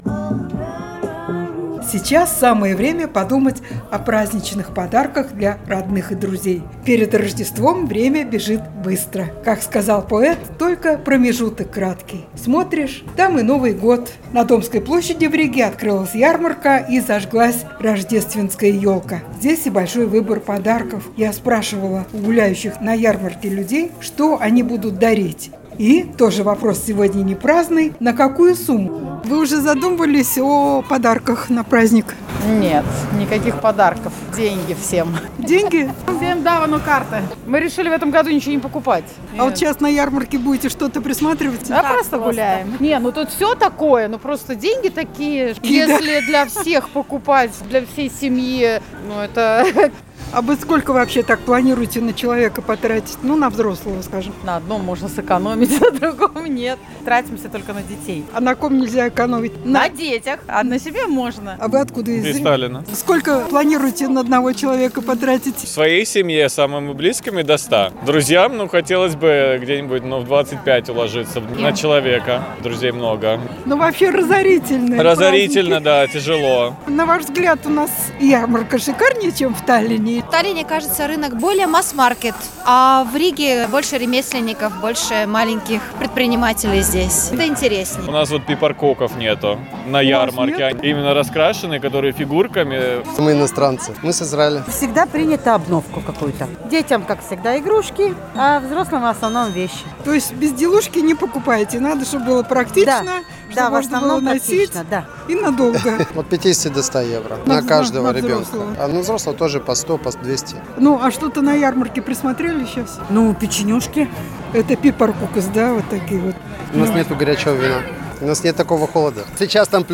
Ей  рассказали, что самые большие подарки либо уже приобретены, либо подыскиваются по подходящей цене.